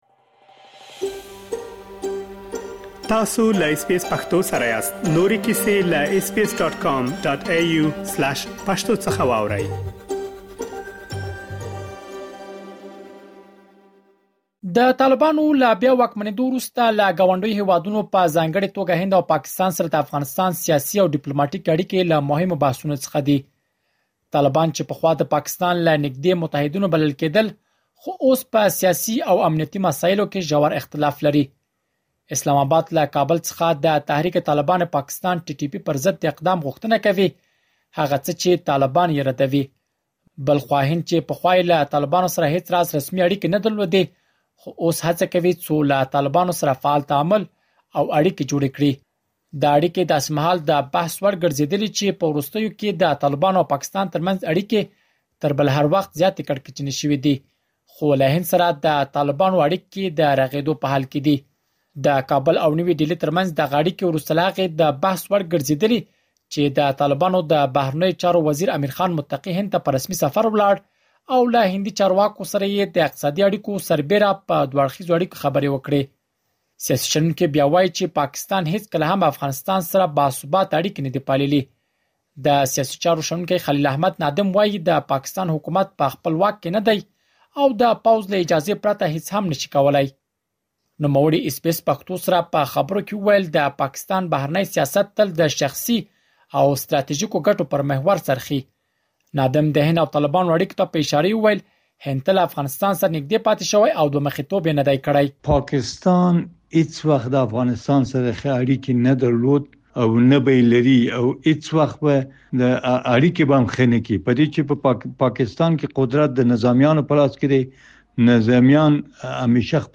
مهرباني وکړئ لا ډېر معلومات په رپوټ کې واورئ.